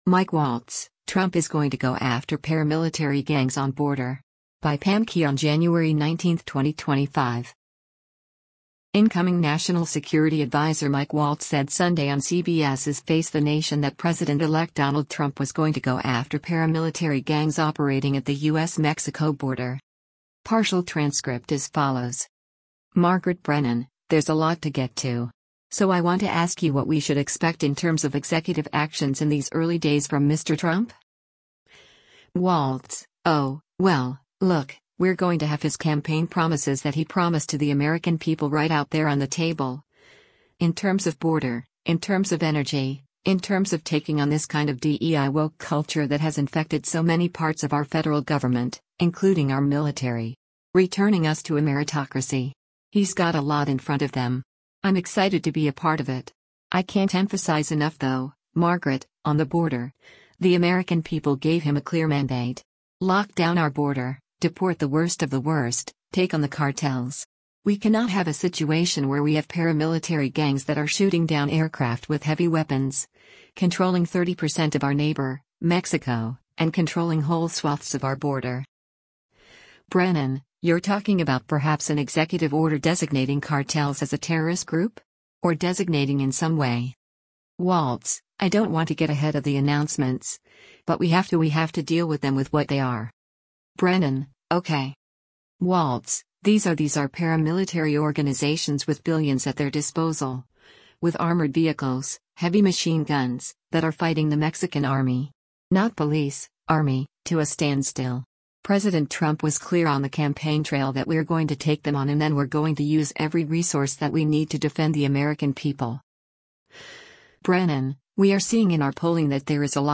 Incoming national security adviser Mike Waltz said Sunday on CBS’s “Face the Nation” that President-elect Donald Trump was “going to go after” paramilitary gangs operating at the U.S.-Mexico border.